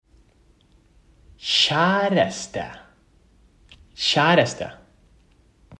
Learn how to pronounce “Kjæreste” in Norwegian
1. K: This is the first letter of Kjæreste, pronounced similar to the English “k” sound.
The “j” is pronounced like the English “y” sound in “yes.” The letter “æ” in Norwegian is a unique vowel sound, similar to the “a” in “cat”, but pronounced more open.
The first “e” is a short vowel sound, similar to the “e” in “bed.”